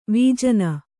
♪ vījana